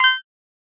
experience_gained_1.ogg